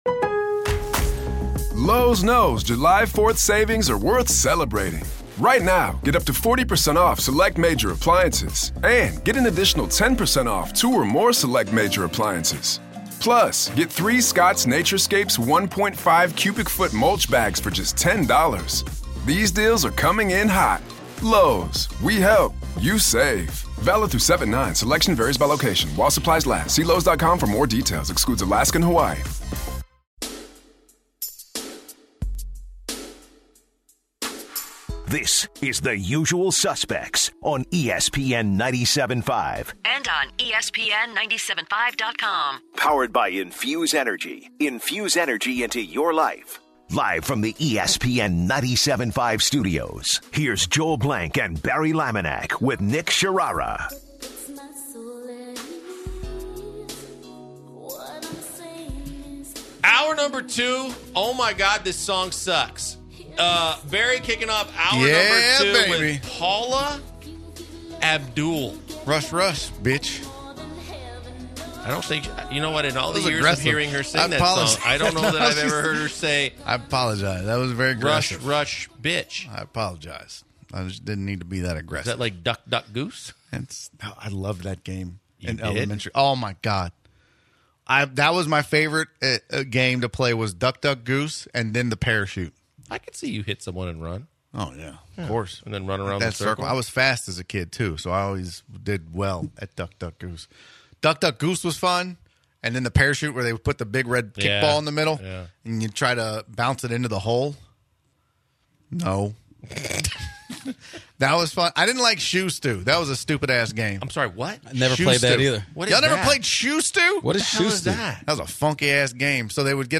They talk about their favorite years of music to listen to and TV shows growing up and what they did when the Rockets won the NBA Championship. The fire alarm goes off and they discuss the multiple ways of dying in the building.